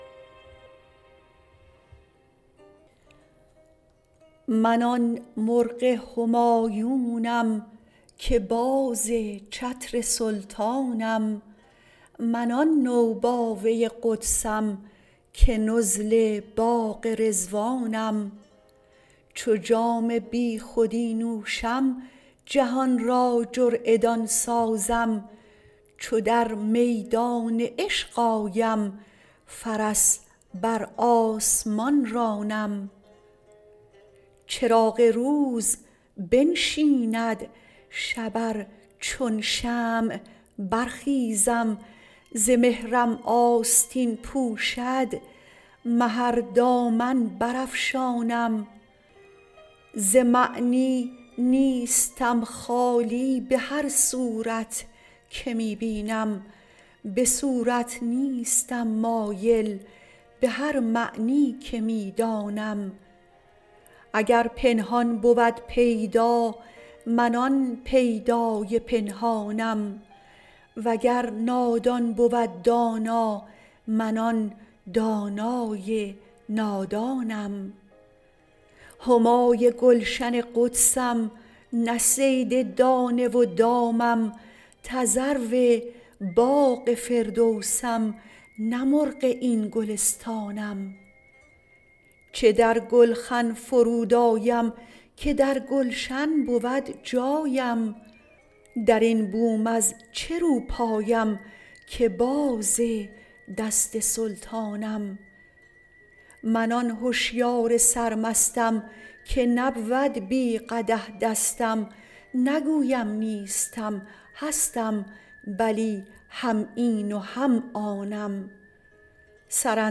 خوانش